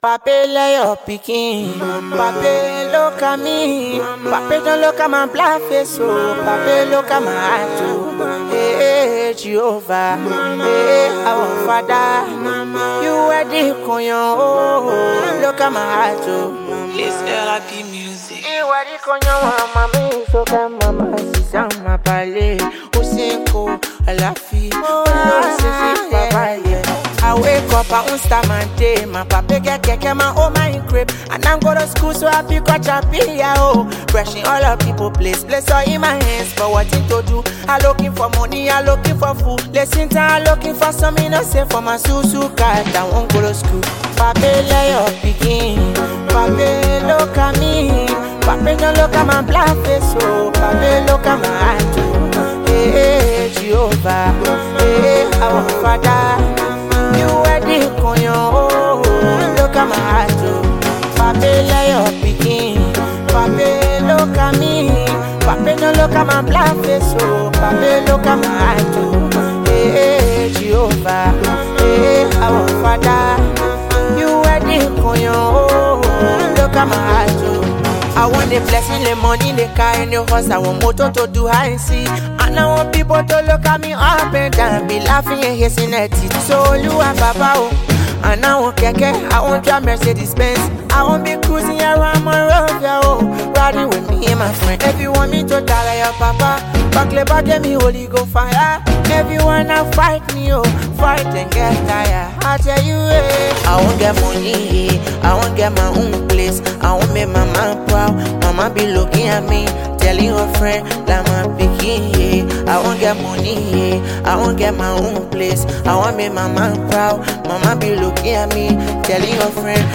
combines heartfelt lyrics with harmonious tunes
Afrobeats